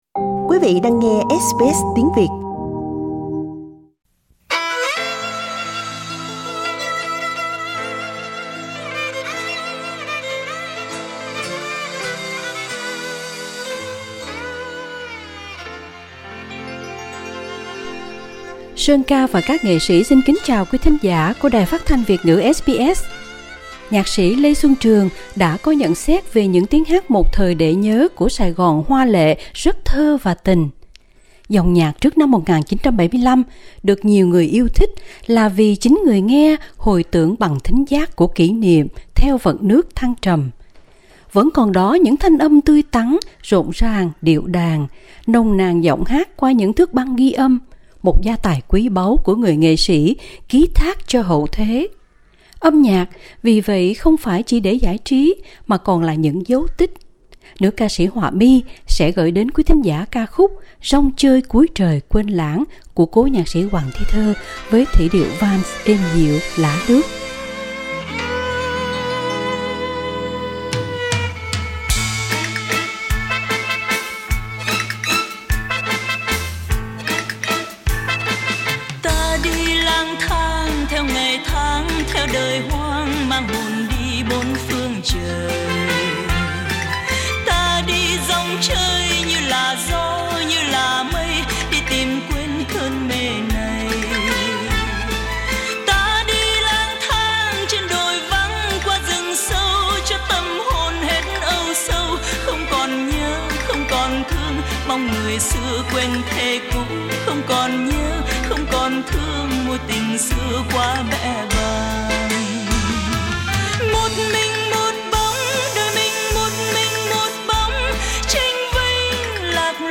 Vẫn còn đó những âm thanh tươi tắn, rộn ràng, điệu đàn, nồng nàn, dòng nhạc trước năm 1975 được nhiều người yêu thích vì người nghe có thể hồi tưởng những kỷ niệm theo vận nước thăng trầm.